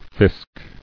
[fisk]